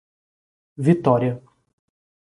Pronounced as (IPA) /viˈtɔ.ɾi.ɐ/